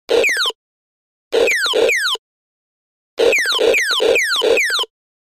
Детский электрический игрушечный пистолет для игры